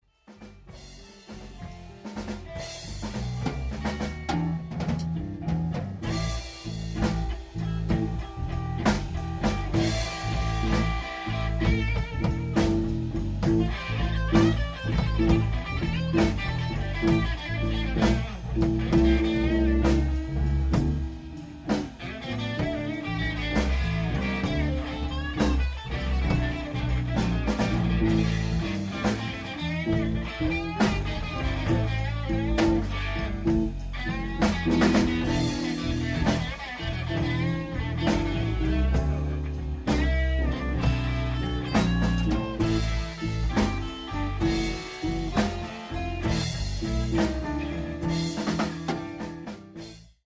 Мой первый тест нового диктофона Zoom H1
На записи (фрагмент): положение - в углу комнаты (около 1 метра от пола), звук ненаправленный; уровень записи - автоматический. Местоположение диктофона невыгодное, ну уж где удалось его приткнуть (на кучке из барабанов:)). Соло: гитара типа -стратокастер- (синглы); усилитель - какой-то Marshall (голова+кабинет); примочка - Boss BD-2.